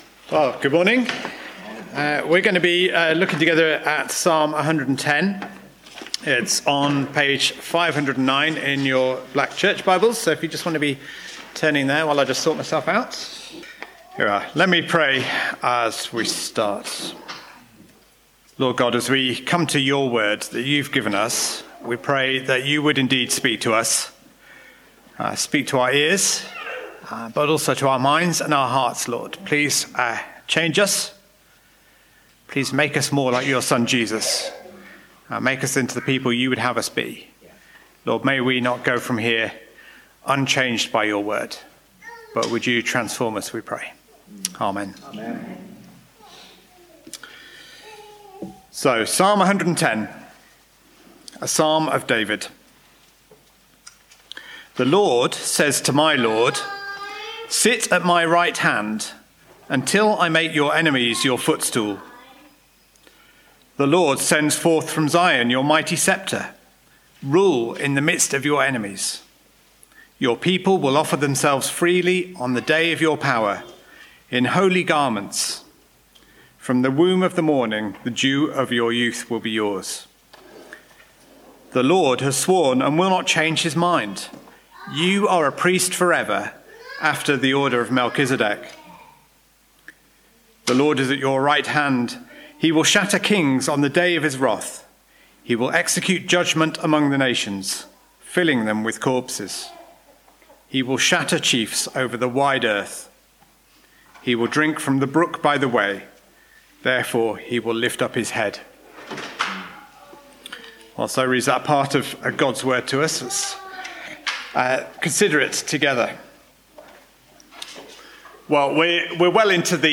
1-7 Service Type: Preaching We are to worship Jesus who rules the nations